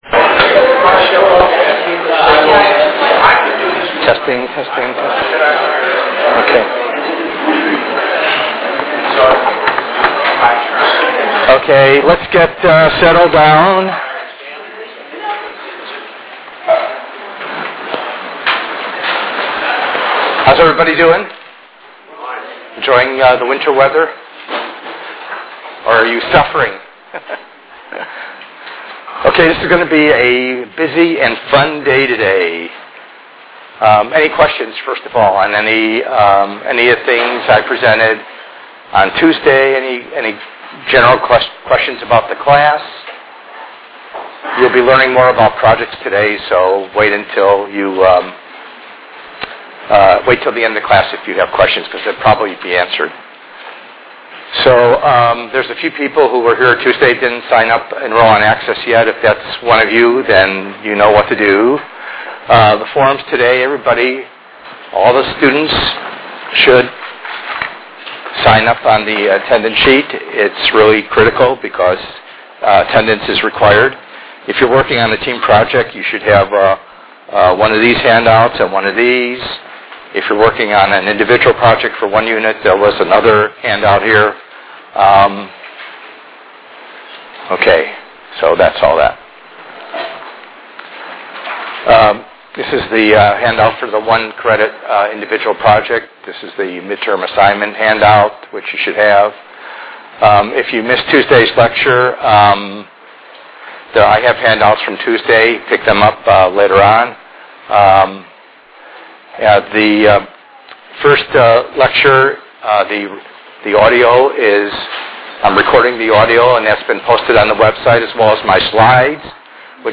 ENGR110/210: Perspectives in Assistive Technology - Lecture 01b